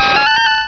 Cri de Girafarig dans Pokémon Rubis et Saphir.